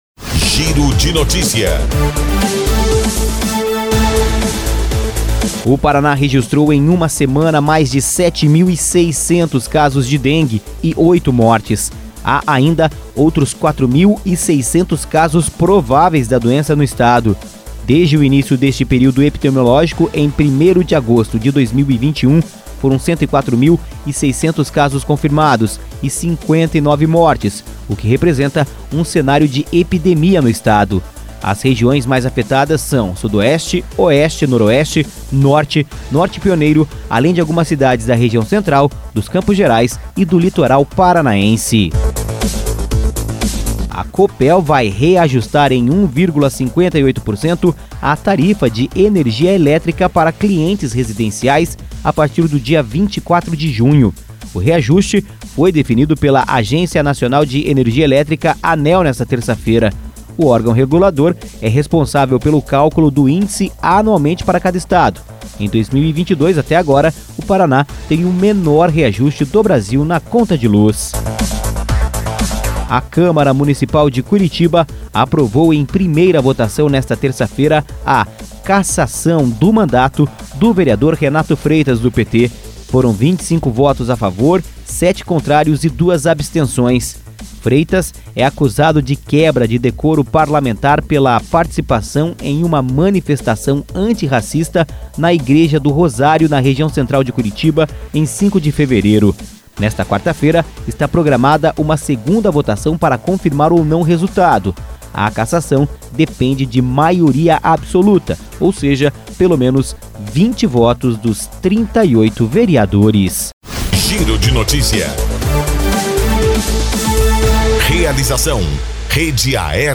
Giro de Notícias – Edição da Manhã